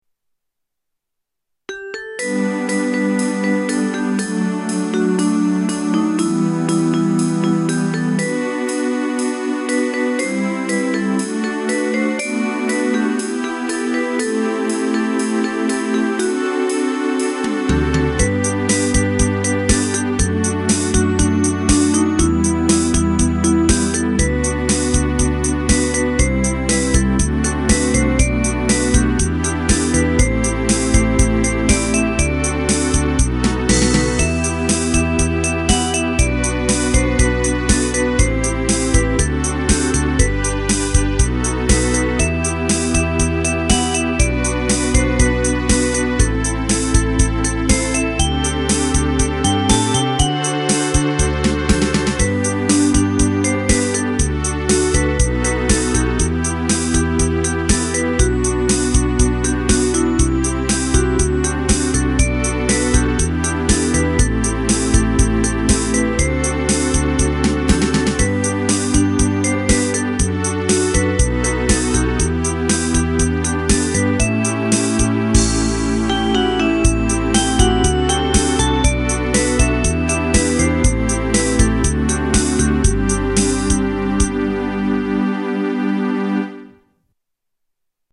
DTM
1:28 明るめ 恋？